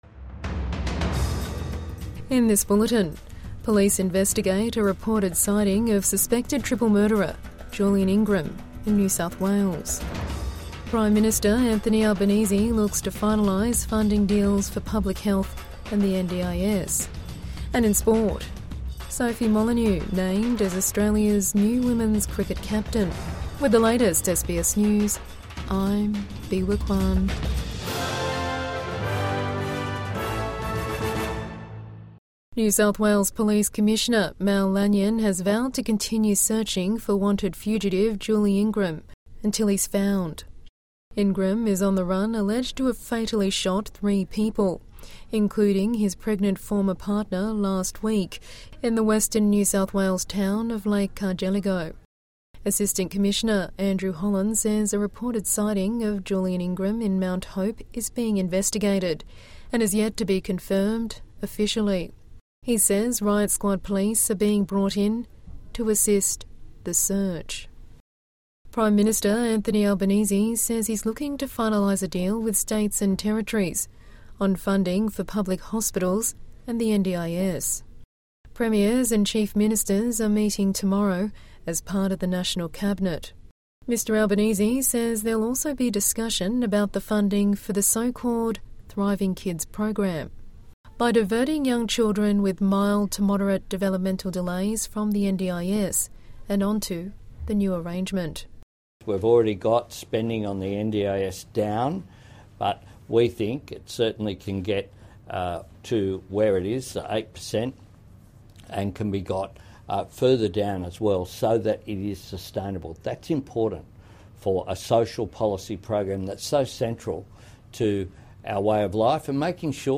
Evening News Bulletin 29 January 2026